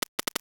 NOTIFICATION_Subtle_09_mono.wav